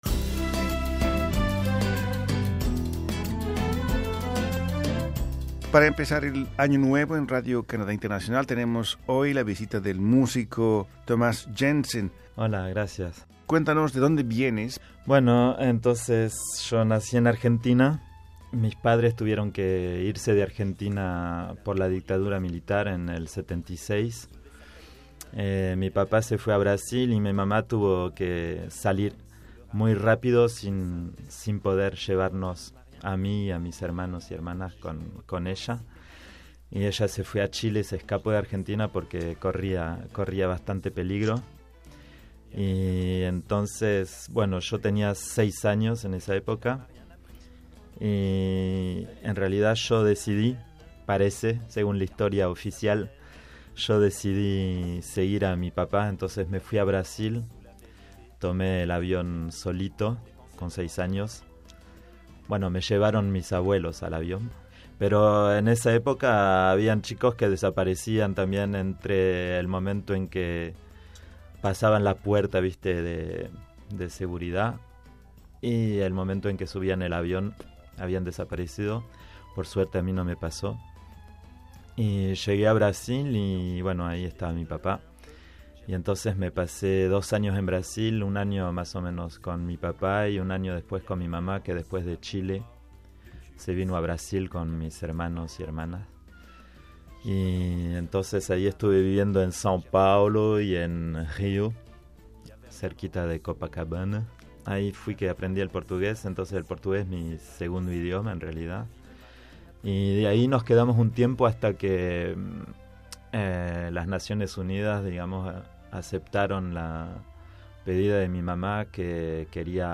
llegó a los estudios de Radio Canadá Internacional para conversar sobre sus veinte años de vida musical